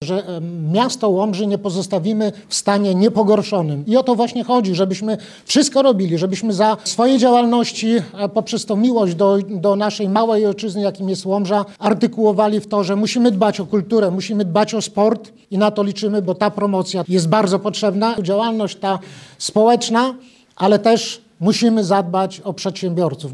Musimy pracować mocno na rzecz naszej małej ojczyzny i przyszłych pokoleń – mówił podczas wczorajszej konferencji wicemarszałek województwa podlaskiego – Marek Olbryś